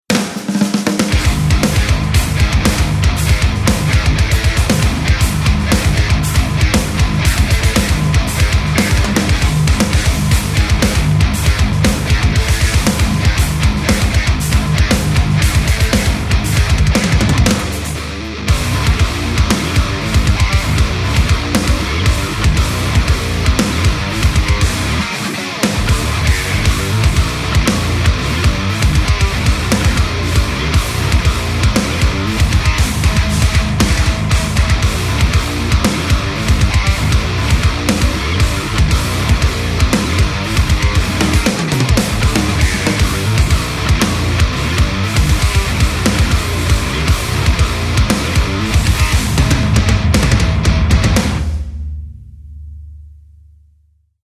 • Una biblioteca versátil de baterías para rock, metal, pop, electrónica y demás
Invasion Audio Demos
SALVAJE Y SIN COMPLEJOS
Fiel al más puro metal, esta biblioteca se basa en un kit de batería descomunal, con bombos y cajas de lo más agresivo, realzados por una serie de timbales perfectamente afinados y una avalancha de platos, desde campanas minúsculas y splashes hasta chinas y crashes monstruosos.